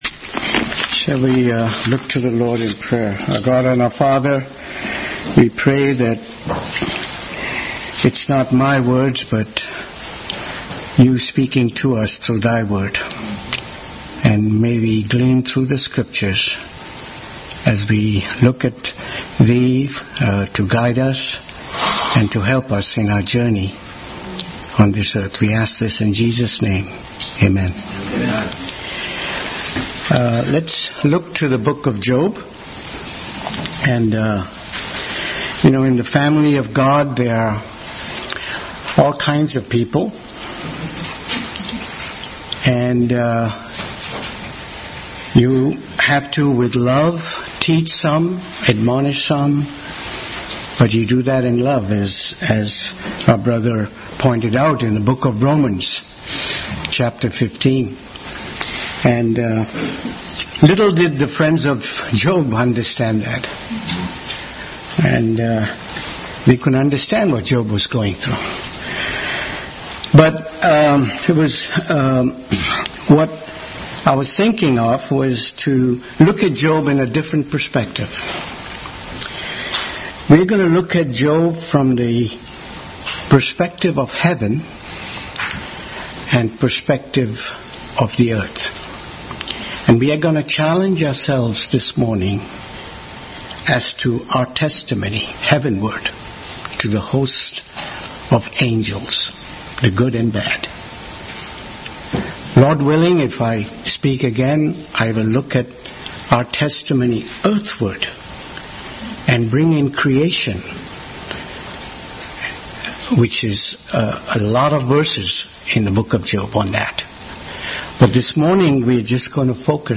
Bible Text: Job 1:1-12, 3:12, 19:25, 9:33, Hebrews 7:25, Ephesians 3:9-11, 1 Corinthians11:3-10 | Family Bible Hour message – Job’s Testimony Heavenward.